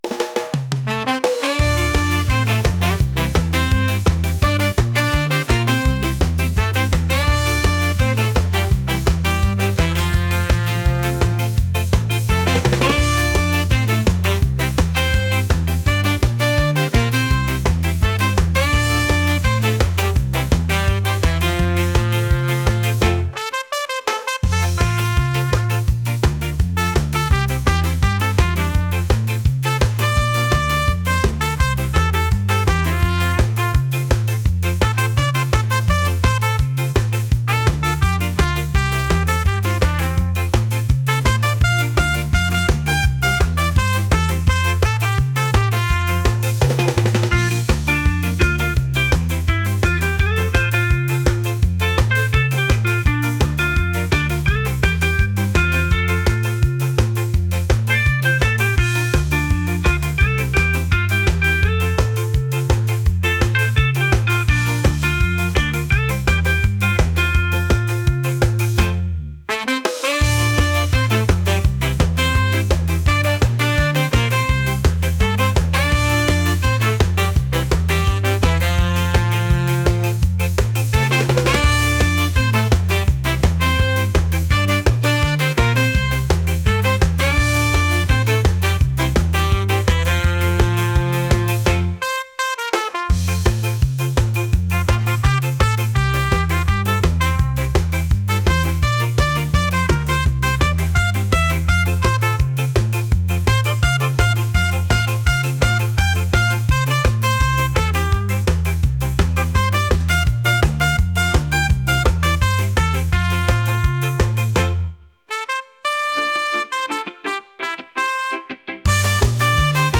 ska | upbeat | reggae